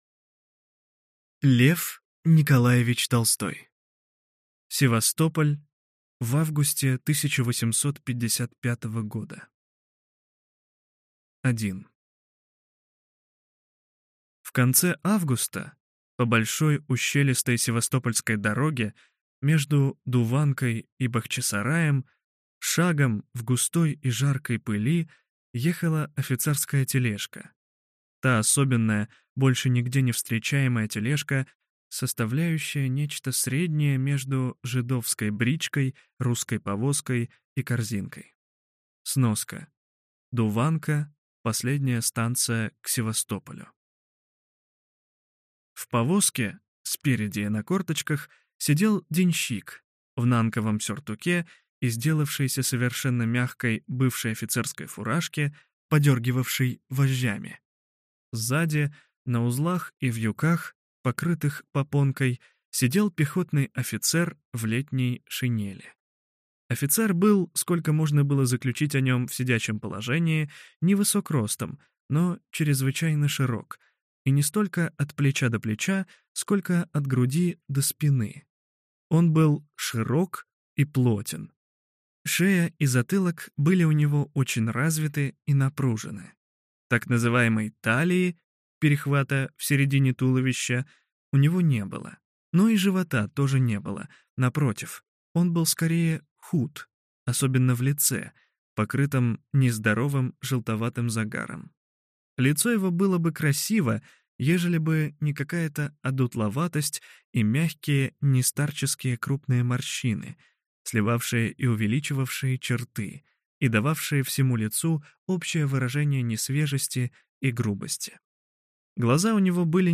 Аудиокнига Севастополь в августе 1855 года | Библиотека аудиокниг